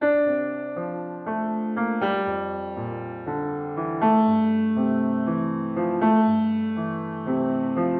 大钢琴的旋律
描述：Dm调的简单钢琴旋律。
Tag: 120 bpm Pop Loops Piano Loops 1.35 MB wav Key : D